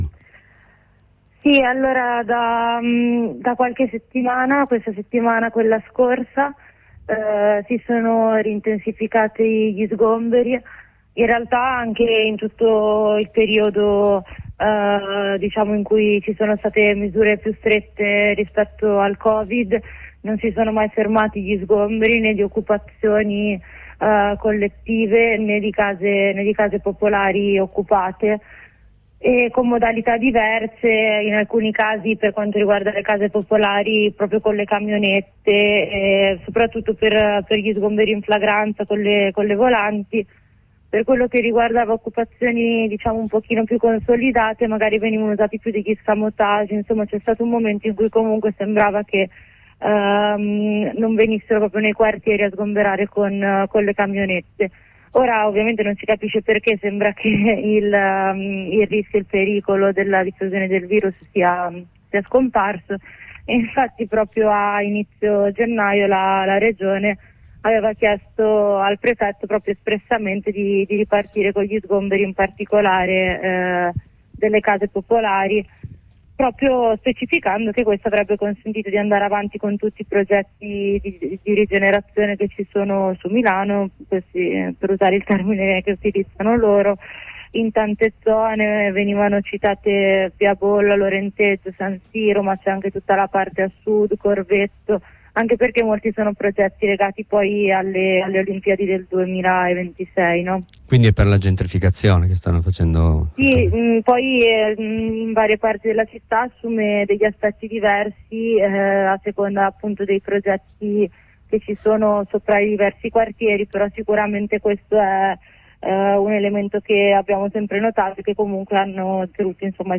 Ne abbiamo parlato con una compagna milanese: